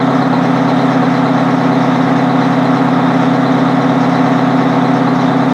Highwayman Truck Idle Slow